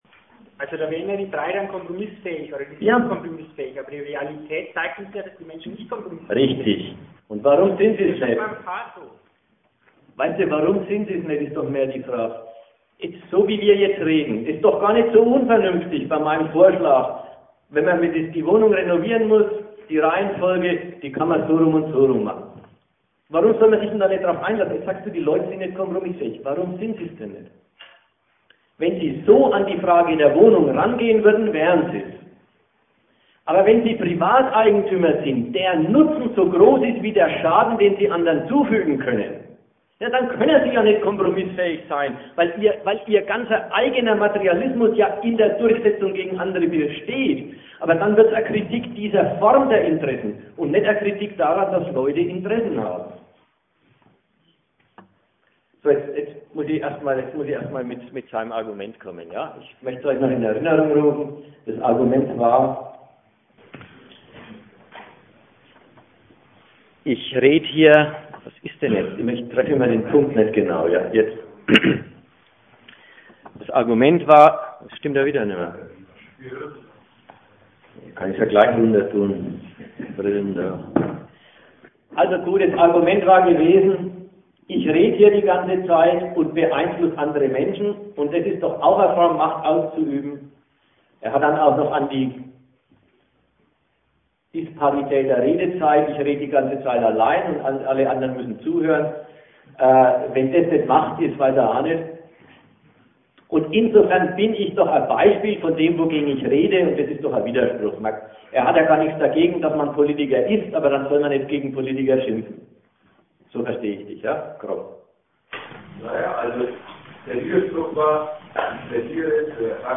Ort Nürnberg
Dozent